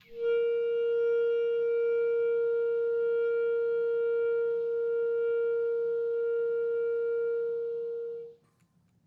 Clarinet
DCClar_susLong_A#3_v1_rr1_sum.wav